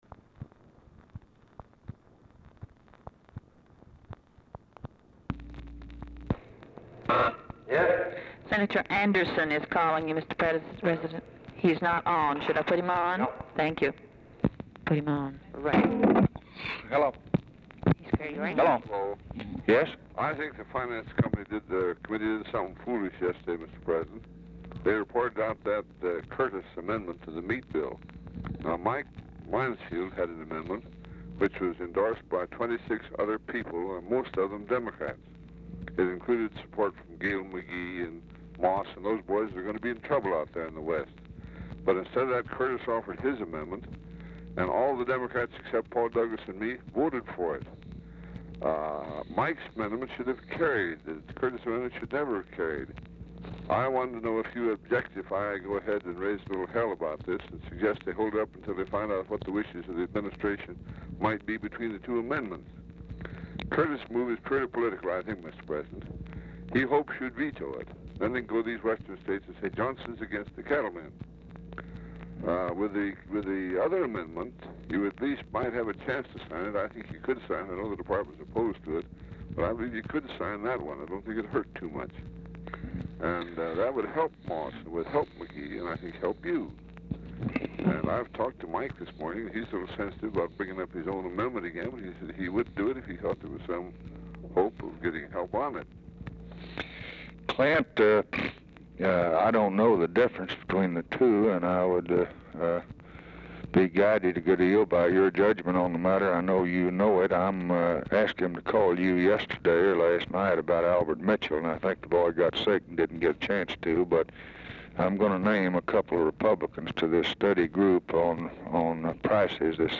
Telephone conversation # 4118, sound recording, LBJ and CLINTON ANDERSON, 7/2/1964, 11:55AM | Discover LBJ
Format Dictation belt
Location Of Speaker 1 Oval Office or unknown location
Specific Item Type Telephone conversation